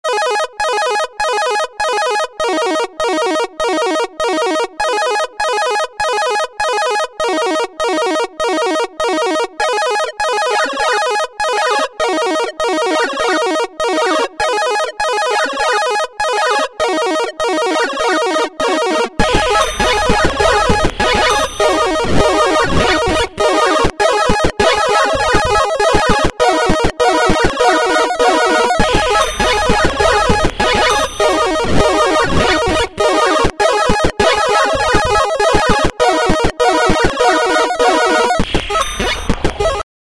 8-бит
8-бит рингтон на звонок.